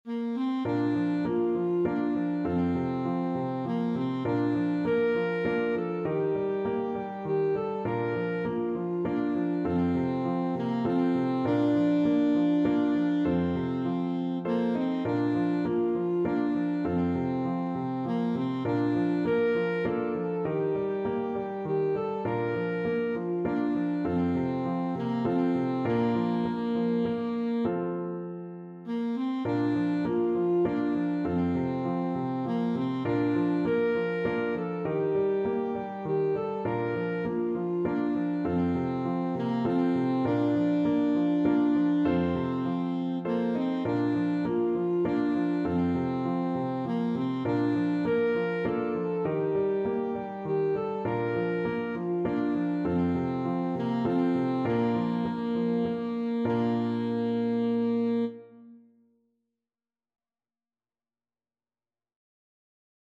Alto Saxophone
Moderato
3/4 (View more 3/4 Music)
Bb4-Bb5